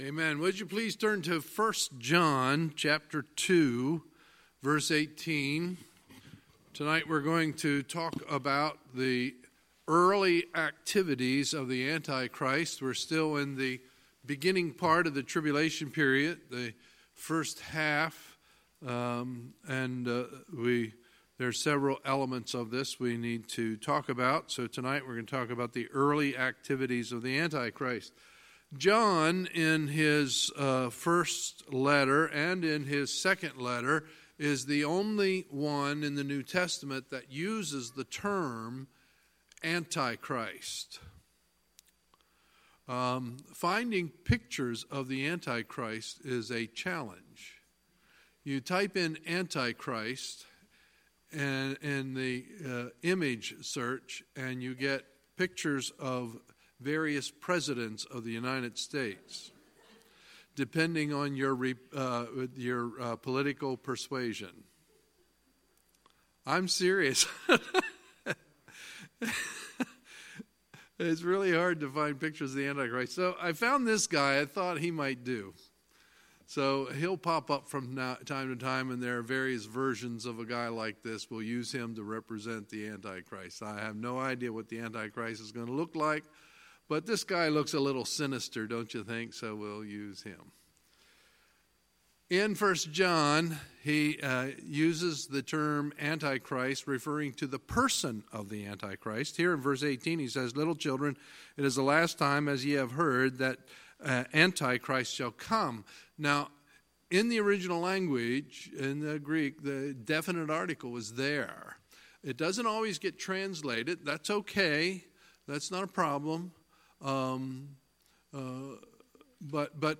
Sunday, December 2, 2018 – Sunday Evening Service